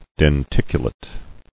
[den·tic·u·late]